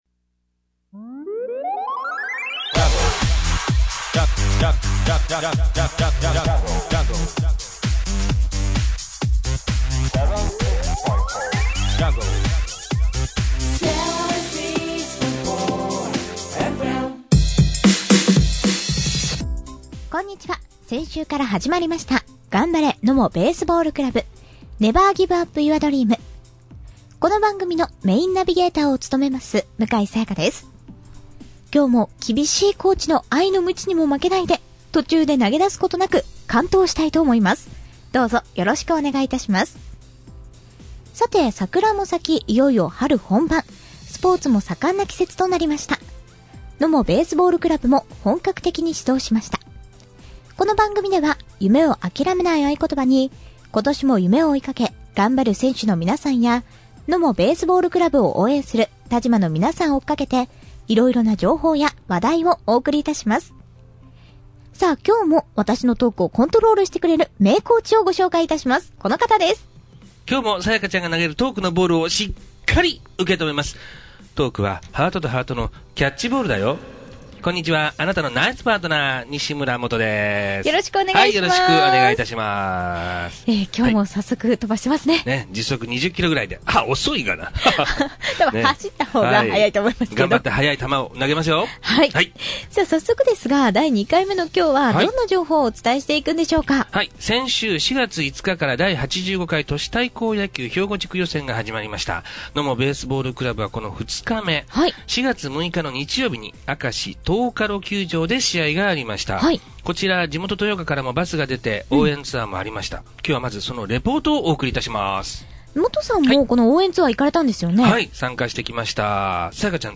豊岡から応援ツアーもあり、地元の方の声援に応え勝利をつかみました。 そのもようからお送りします。